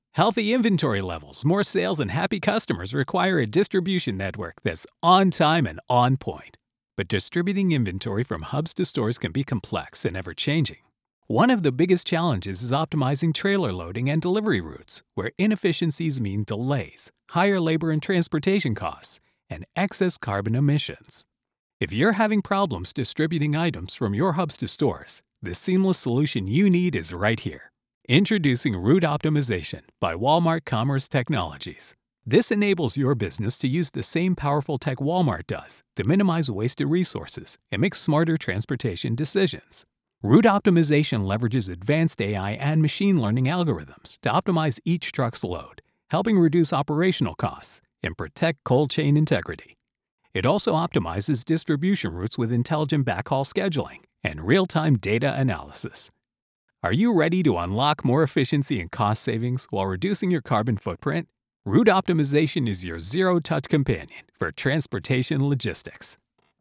Commercial, Natural, Distinctive, Accessible, Versatile
Corporate